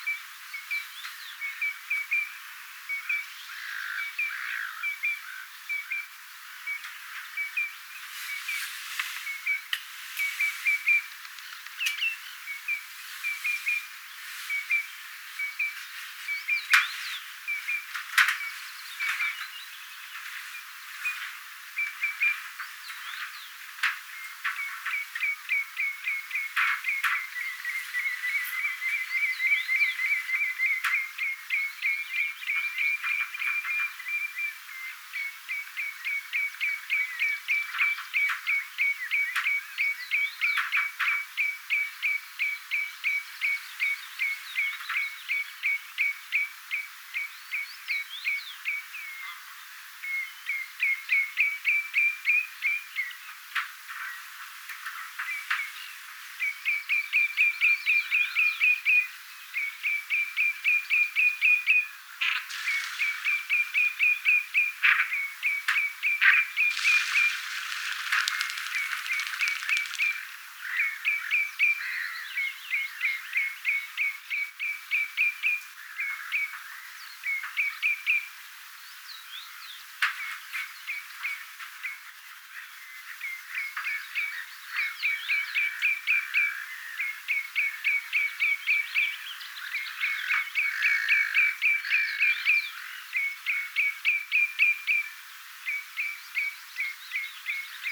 Satoi - välillä aika kovaa.
punajalkaviklot ääntelivät paljon,
kun olin lintutornissa, 1
punajalkaviklot_aantelivat_jatkuvasti_kun_olin_lintutornissa.mp3